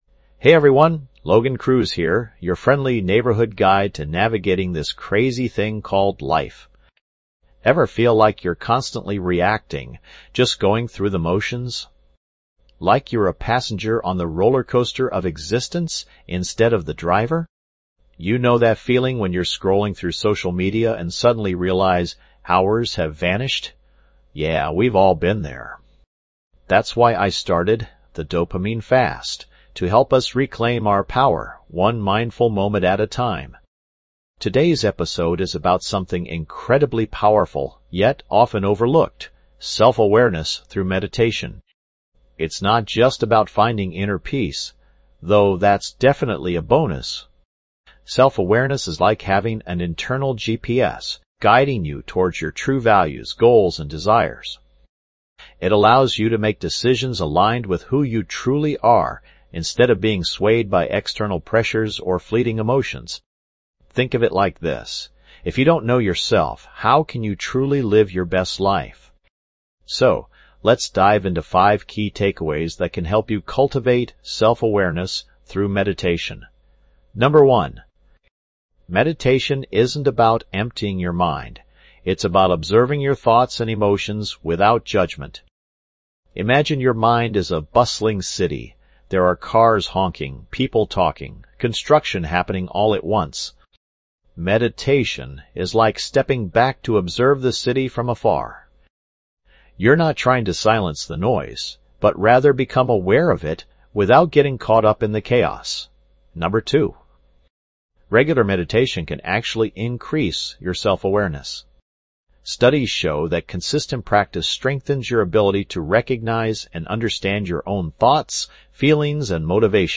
Podcast Category:. Health Wellness Mindfulness Meditation
This podcast is created with the help of advanced AI to deliver thoughtful affirmations and positive messages just for you.